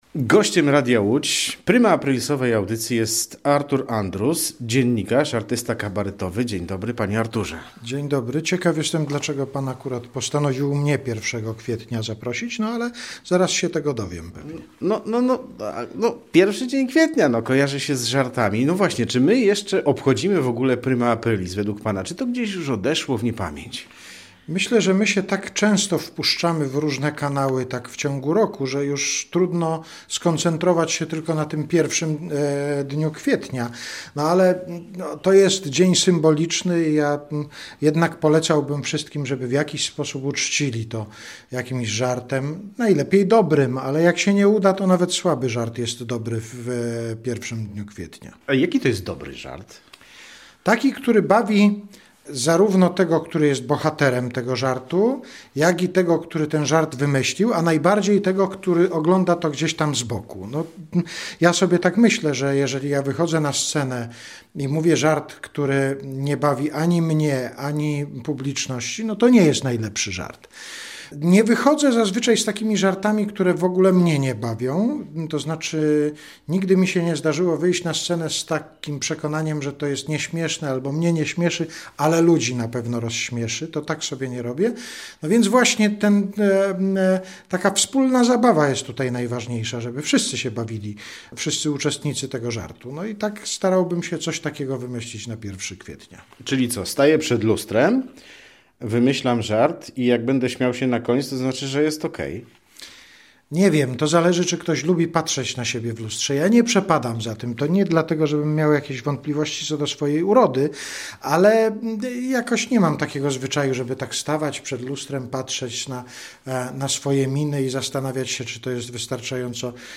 Ludzie z gruntu są zabawni - Artur Andrus na Prima Aprilis [ROZMOWA] - Radio Łódź
Artur Andrus gościem primaaprilioswej audycji